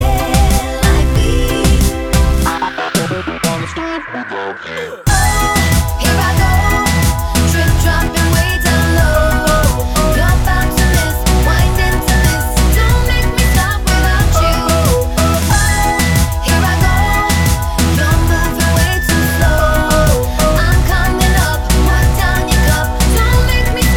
For Solo Female Pop (2010s) 3:35 Buy £1.50